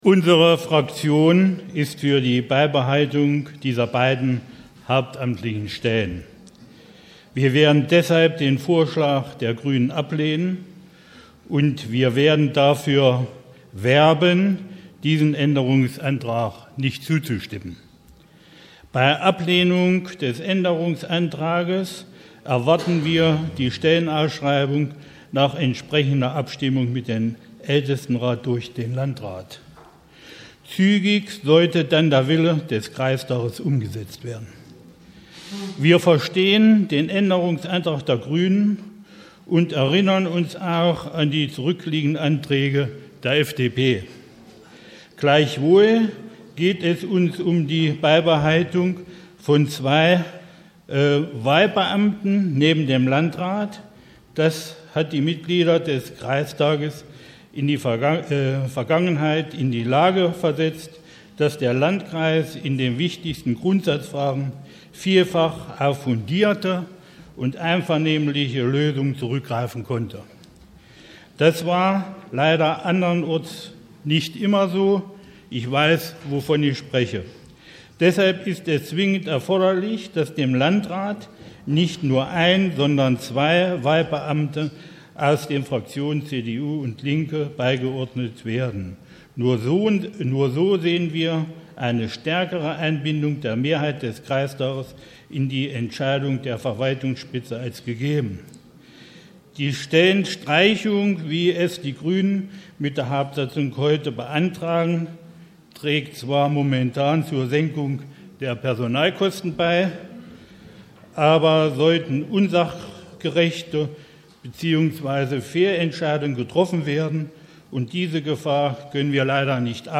Wir haben hier die Wortmeldungen als Audiobeiträge für Sie zusammengestellt, die uns freundlicherweise das Bürgerradio ENNO zur Verfügung gestellt hat.